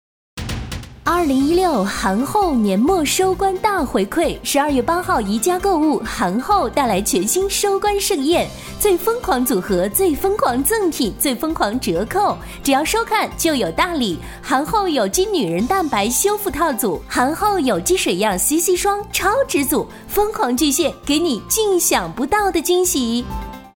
7 女国118_广告_化妆品_韩后_甜美 女国118
女国118_广告_化妆品_韩后_甜美.mp3